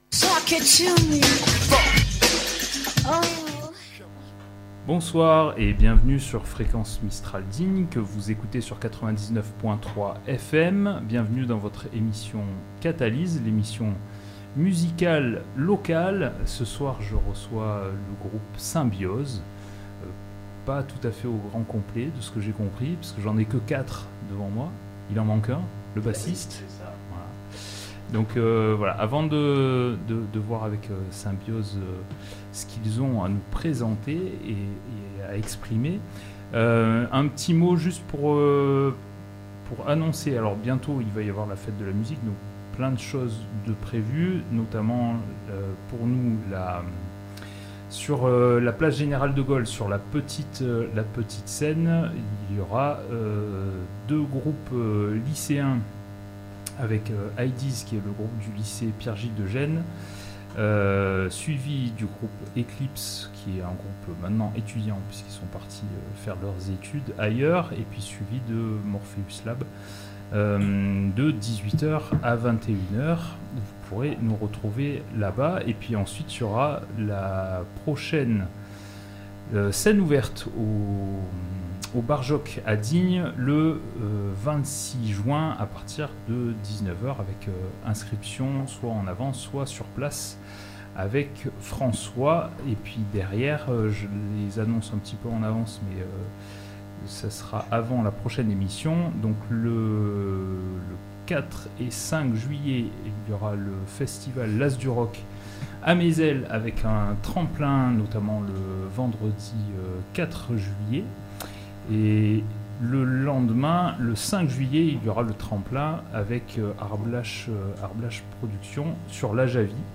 Catalyse Saison 3 sur Fréquence Mistral Digne